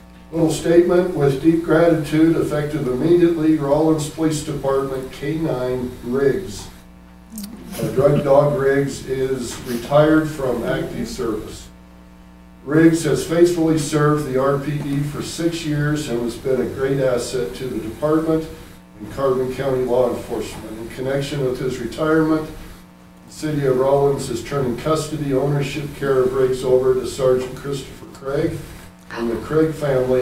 During the March 4th Rawlins city council meeting, City Manager Tom Sarvey made a special announcement. After six years of serving with the Rawlins Police Department, K9 Officer Riggs is retiring.